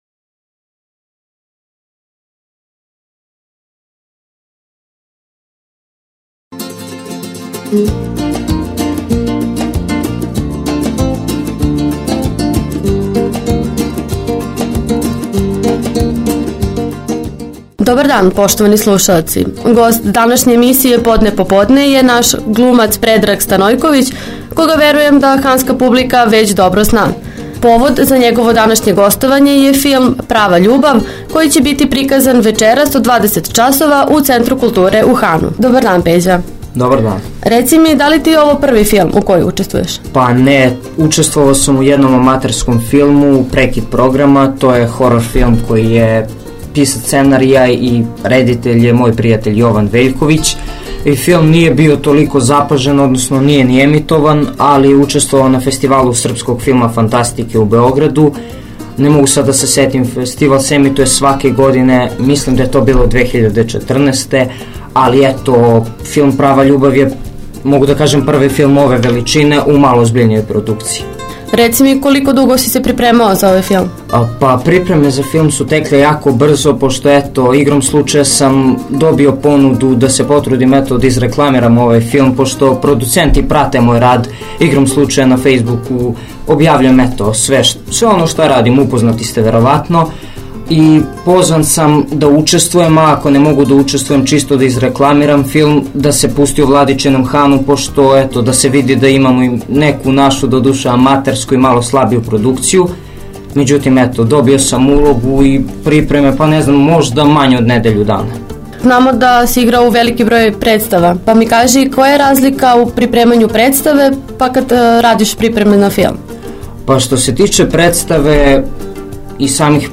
U razgovoru za Radio Han